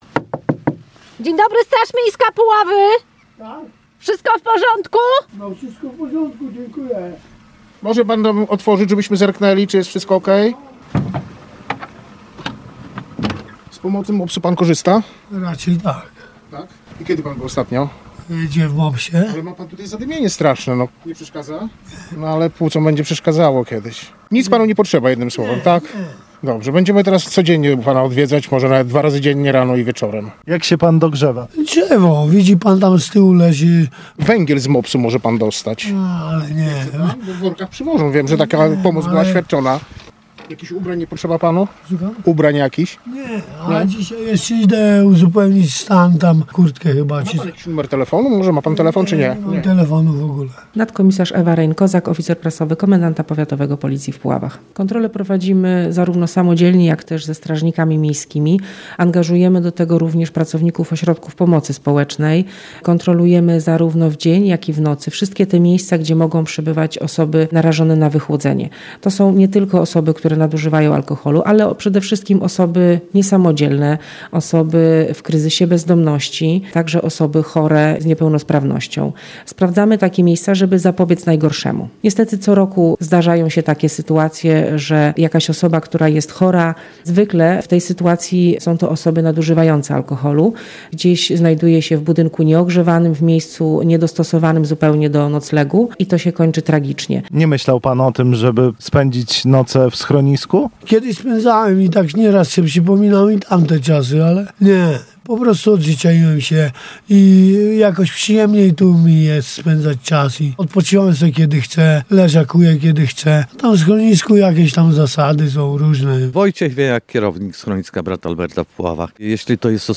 Podczas jednej z takich dzisiejszych kontroli nasz reporter towarzyszył patrolowi straży miejskiej.